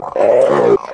zombie_pain1.mp3